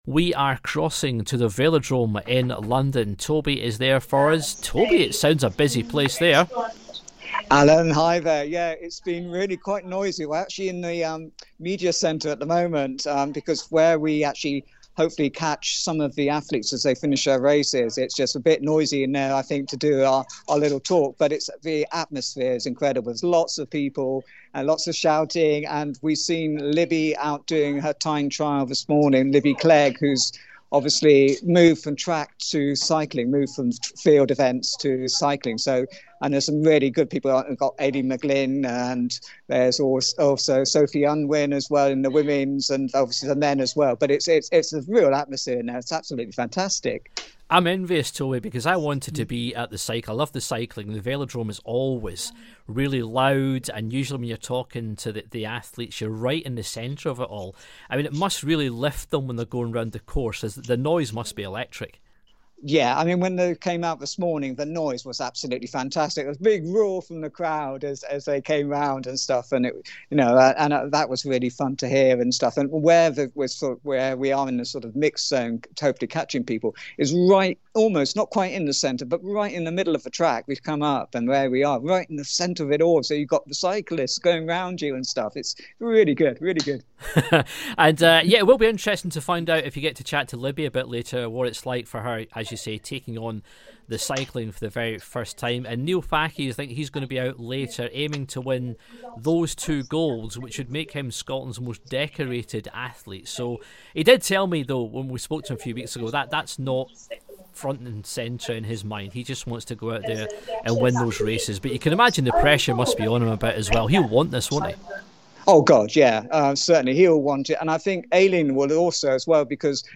LIVE From The Commonwealth Games 2022 - Friday 29th JULY